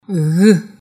ghămă